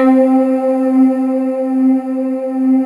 Index of /90_sSampleCDs/USB Soundscan vol.28 - Choir Acoustic & Synth [AKAI] 1CD/Partition D/24-THYLIVOX